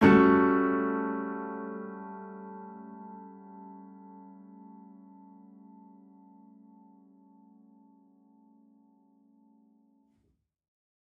Index of /musicradar/gangster-sting-samples/Chord Hits/Piano
GS_PiChrd-A6min7.wav